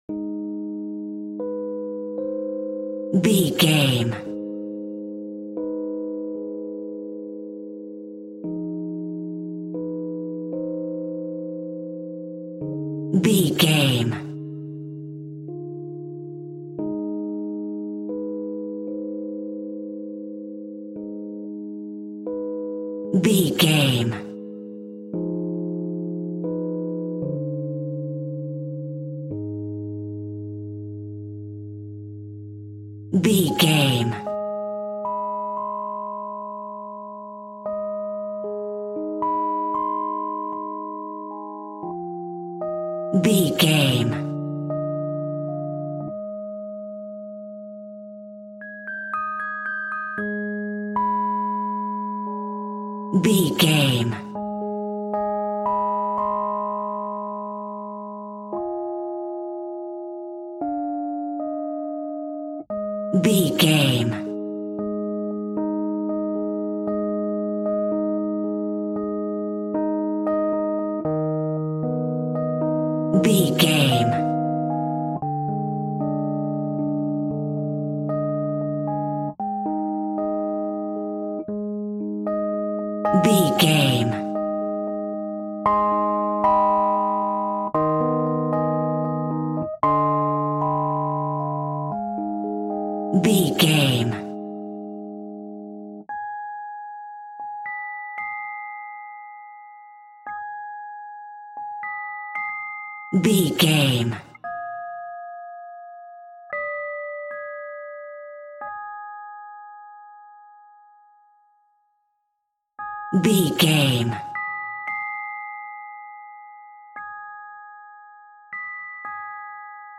Aeolian/Minor
G#
scary
ominous
dark
haunting
eerie
electric piano
horror
creepy